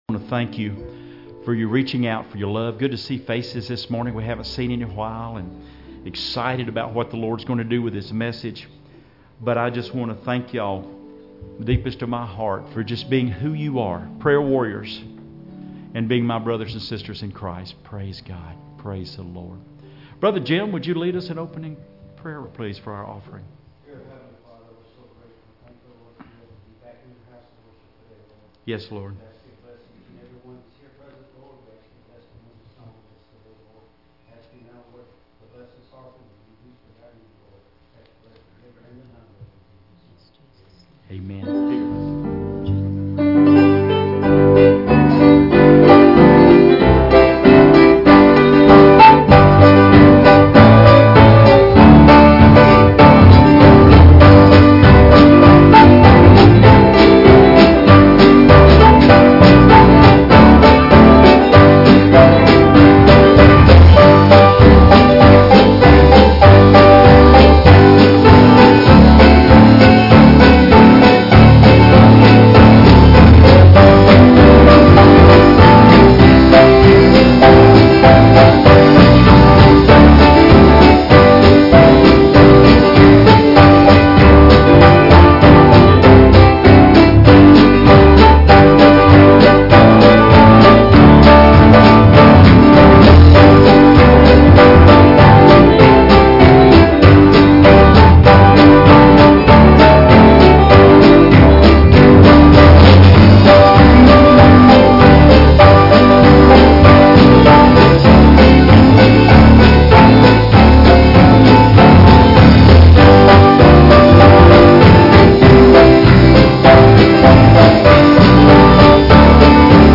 "Daniel 2:19-22" Service Type: Sunday Morning Services Topics